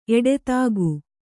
♪ eḍetāgu